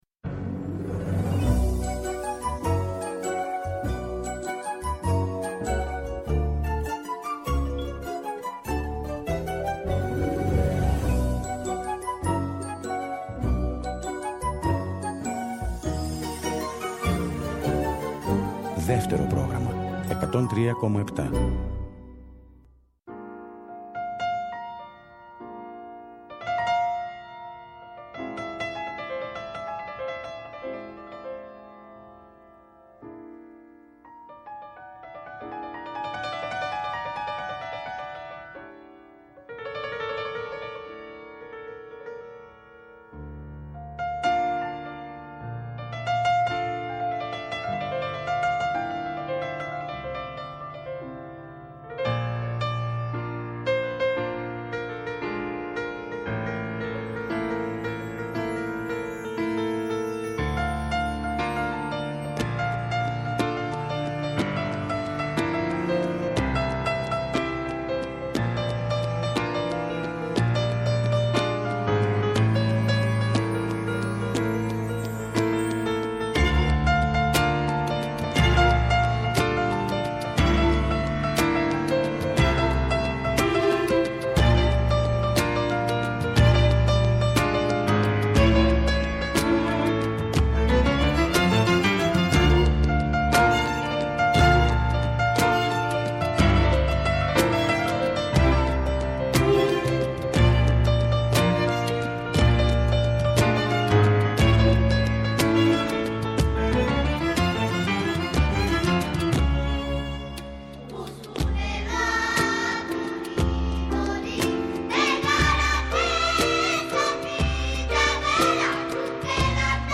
Στις Πλανόδιες Μουσικές ακούμε τραγούδια που έχουμε αγαπήσει και τραγούδια που ίσως αγαπήσουμε.